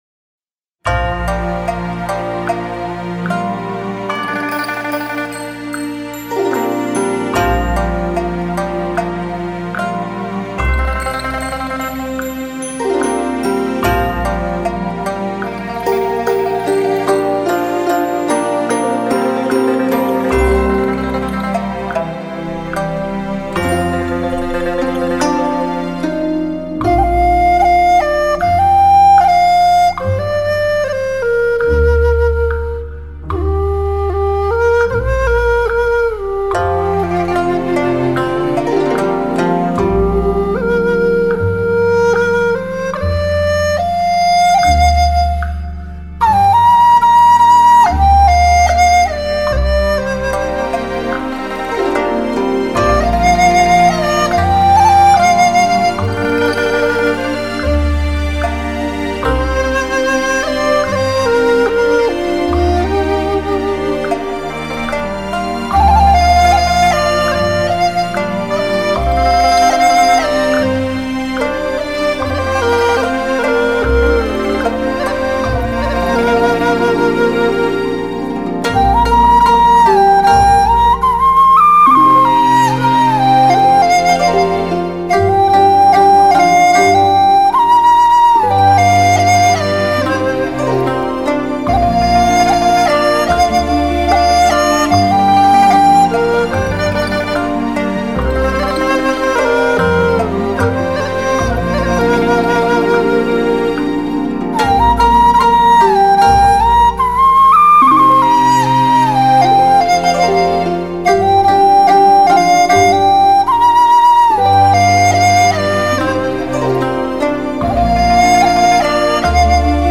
音乐风格：流行
演奏乐器：笛子